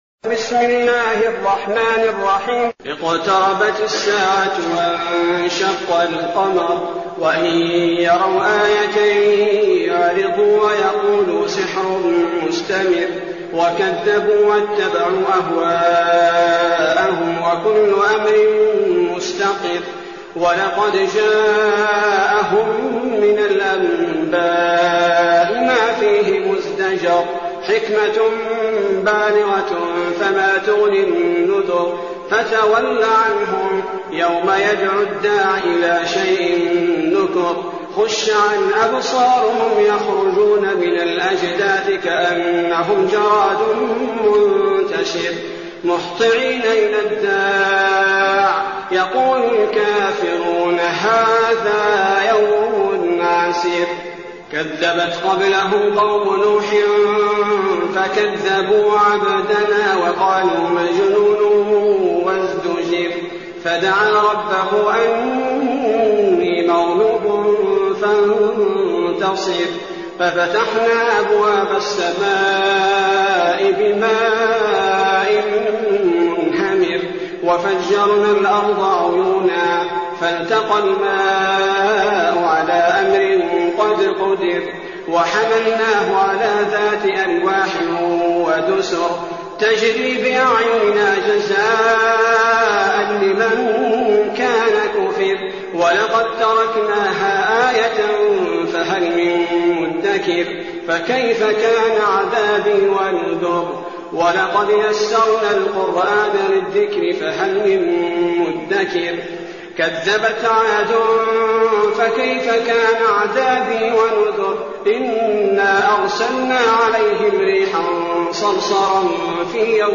المكان: المسجد النبوي الشيخ: فضيلة الشيخ عبدالباري الثبيتي فضيلة الشيخ عبدالباري الثبيتي القمر